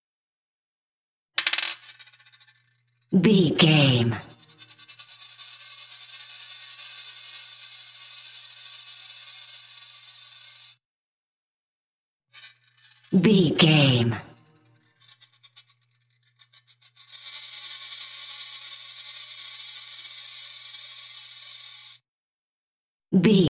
Coin spin glass table
Sound Effects
foley